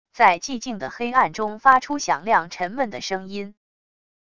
在寂静的黑暗中发出响亮沉闷的声音wav音频